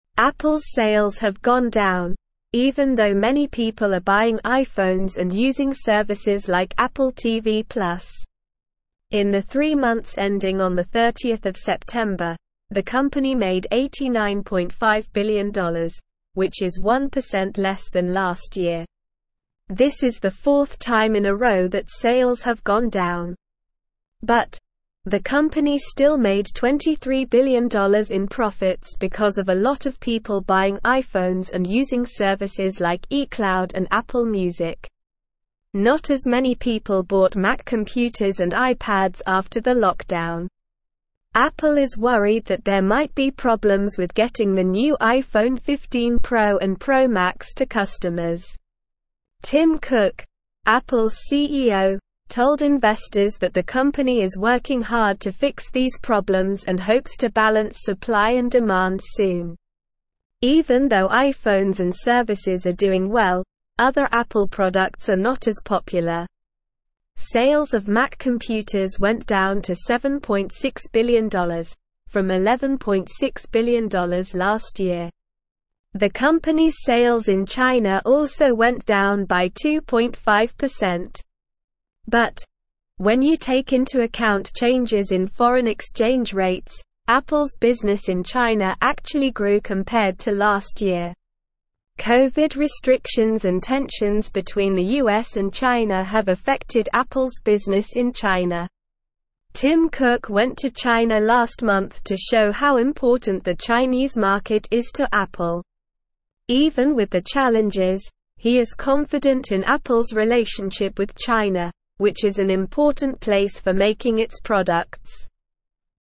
Slow
English-Newsroom-Upper-Intermediate-SLOW-Reading-Apples-Sales-Decline-Despite-Strong-iPhone-Demand.mp3